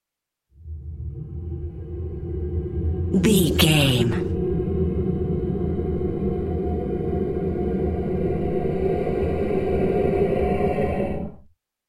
Wind evil slow growl air
Sound Effects
Atonal
scary
ominous
haunting
eerie